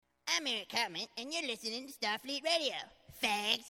STARFLEET Radio Ident Bumper